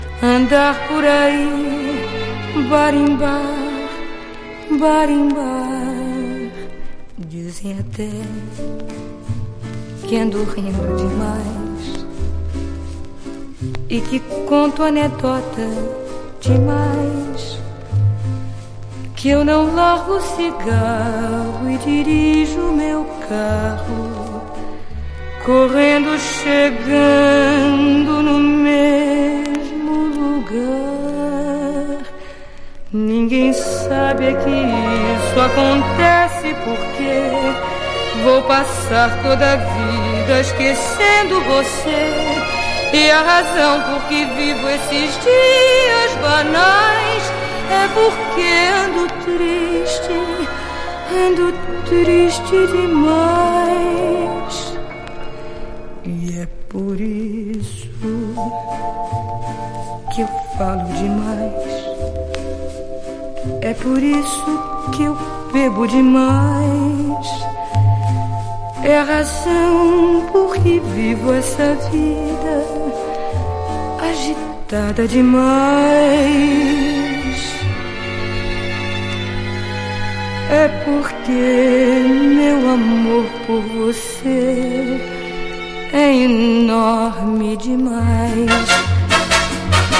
控えめなアレンジとしっとりと歌いあげる楽曲が中心の1959年作。